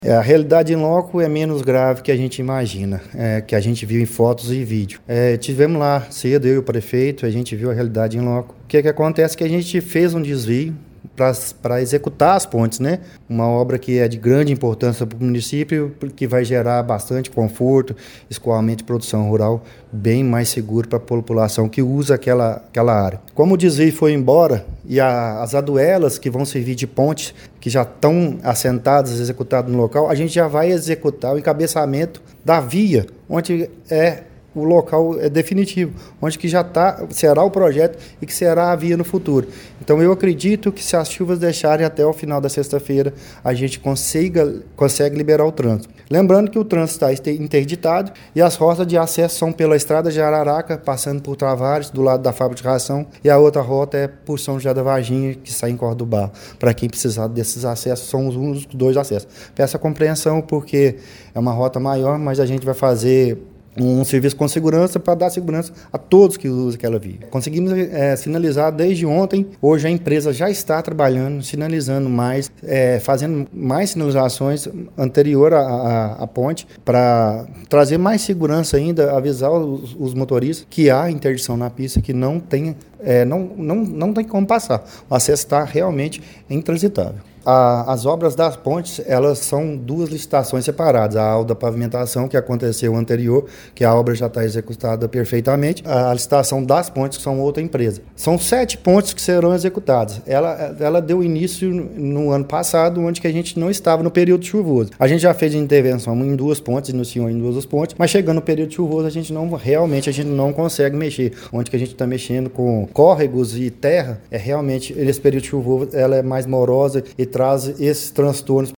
Durante coletiva de imprensa realizada nesta segunda-feira, 5, o secretário municipal de Obras e Infraestrutura, André Lara Amaral, apresentou um panorama das medidas adotadas pelo Município para enfrentar os danos provocados pelas fortes chuvas registradas no fim de semana passado.